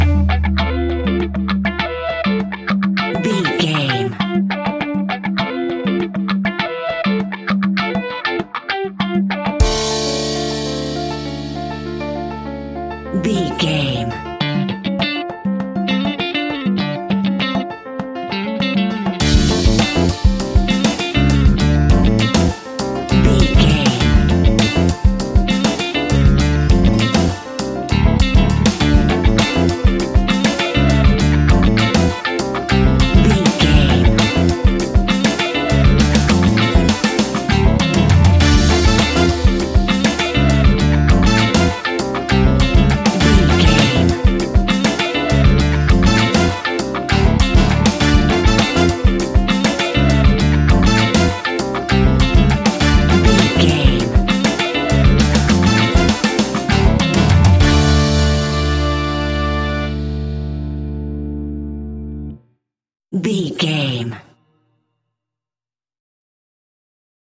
Aeolian/Minor
latin
World Music
uptempo
bass guitar
percussion
brass
saxophone
trumpet
fender rhodes
clavinet